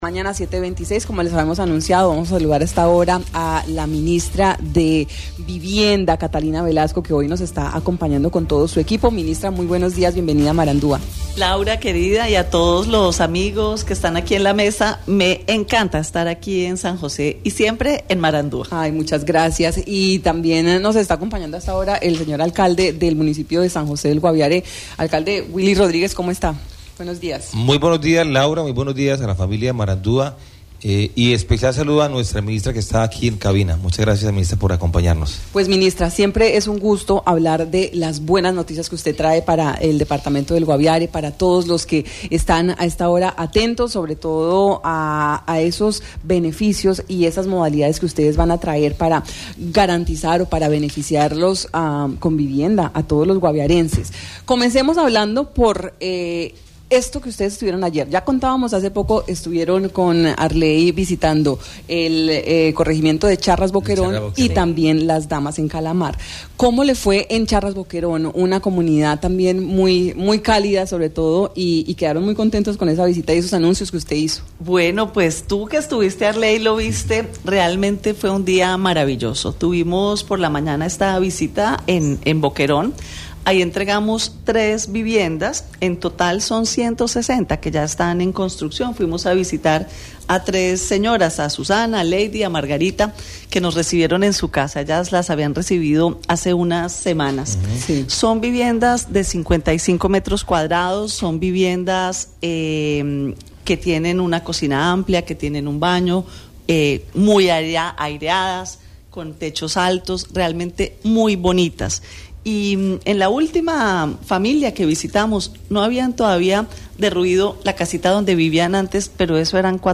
La ministra de Vivienda anunció en Marandua Noticias que hoy estará visitando lugares donde se están llevando a cabo la entrega de viviendas al pueblo Jiw